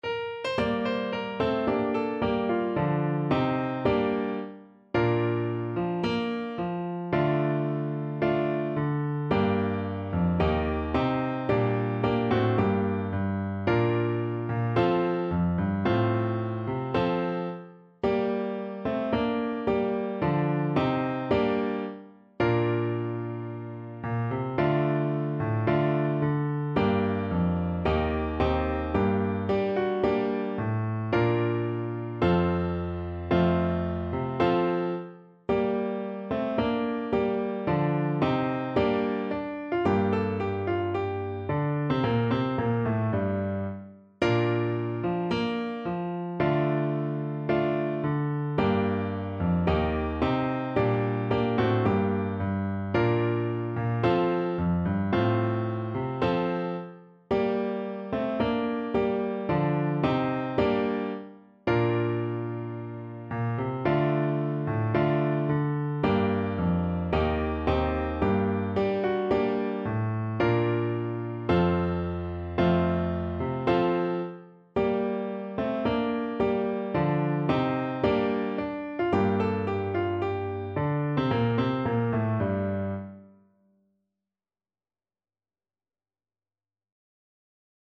~ = 110 Allegro (View more music marked Allegro)